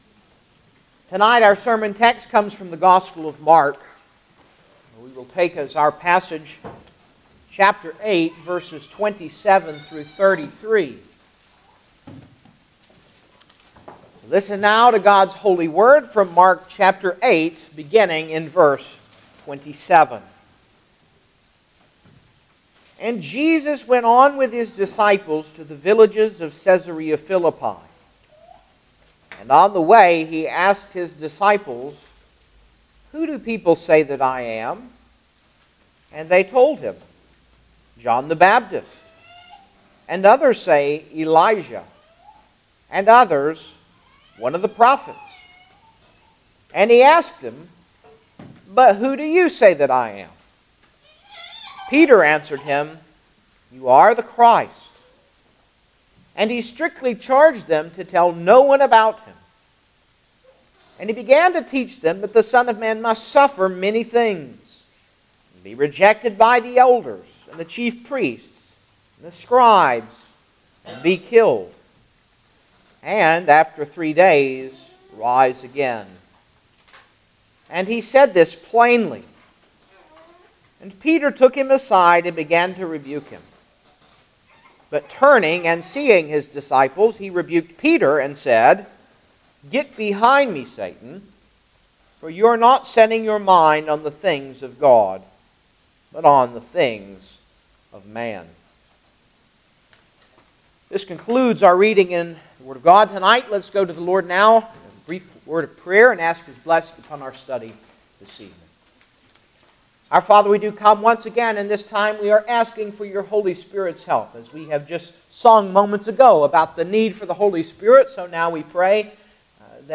Gospel of Mark Passage: Mark 8:27-33 Service Type: Sunday Evening Service Download the order of worship here .